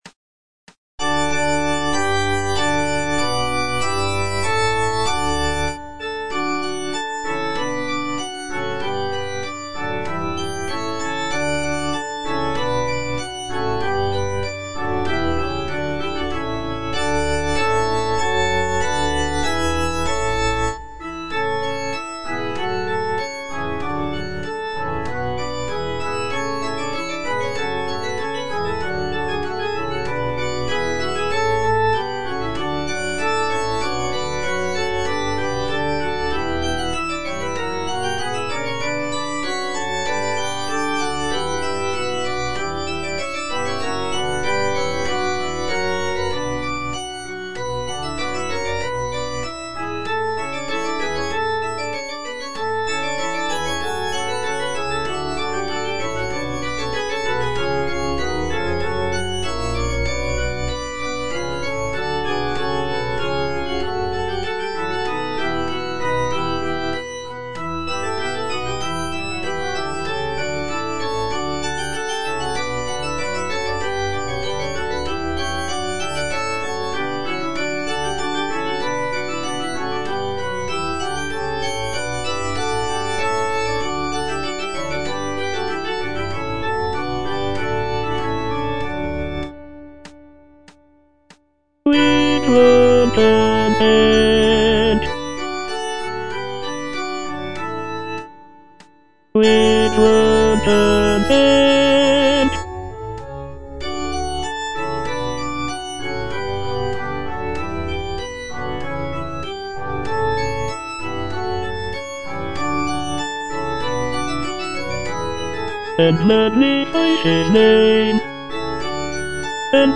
G.F. HÄNDEL - O PRAISE THE LORD WITH ONE CONSENT - CHANDOS ANTHEM NO.9 HWV254 (A = 415 Hz) O praise the Lord - Tenor (Voice with metronome) Ads stop: auto-stop